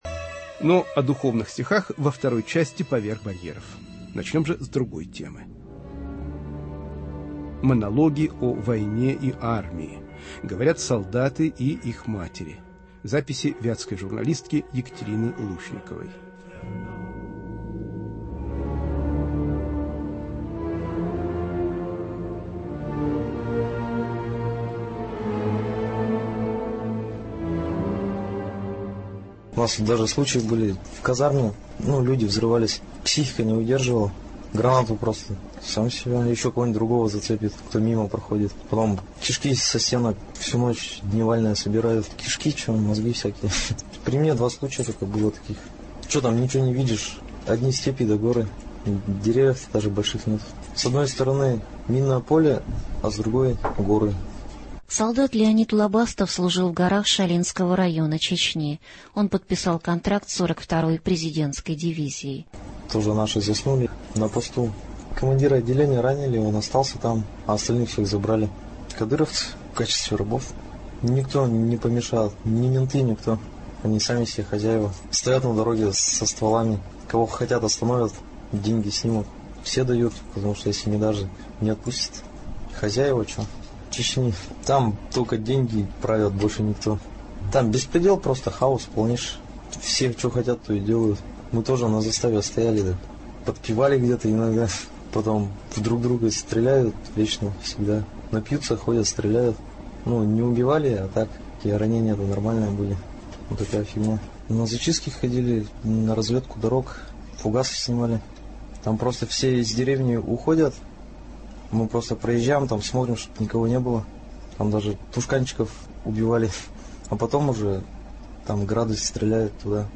Монологи о войне и армии. Говорят солдаты и их матери.